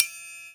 • Verby Ride Single Hit D Key 02.wav
Royality free ride tuned to the D note. Loudest frequency: 7181Hz
verby-ride-single-hit-d-key-02-hz2.wav